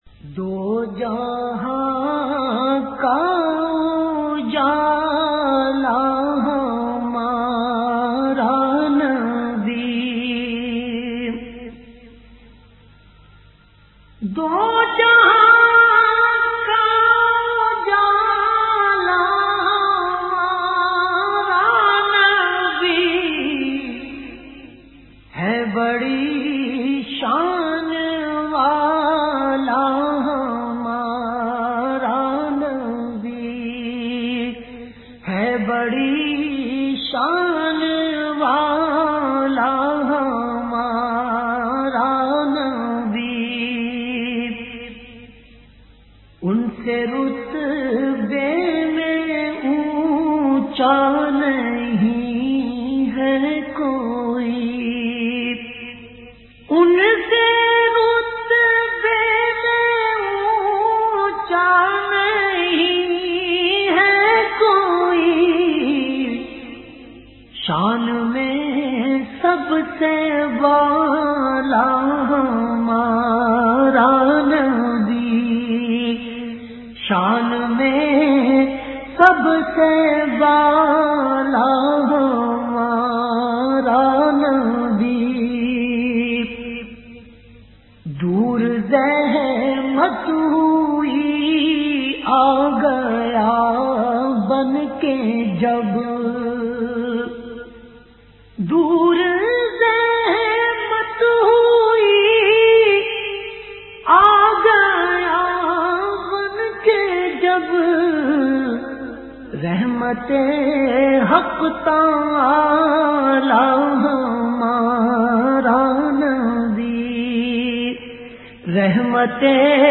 Best Islamic Naats